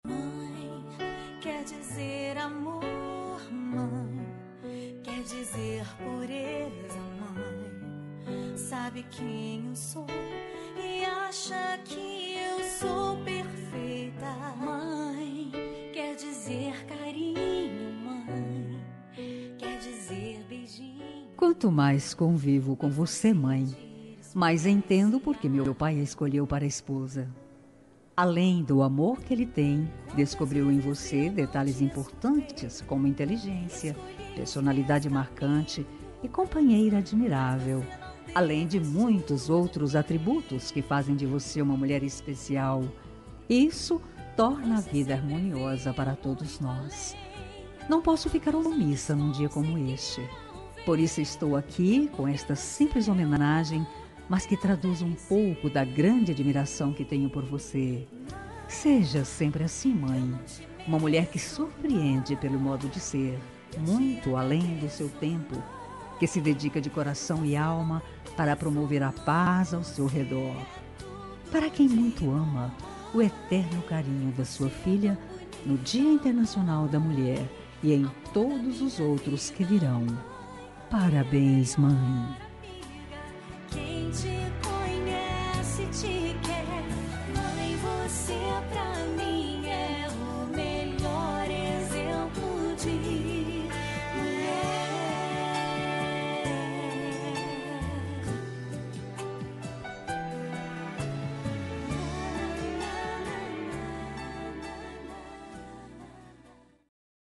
Telemensagem Dia das Mulheres para Mãe – 01 Voz Feminina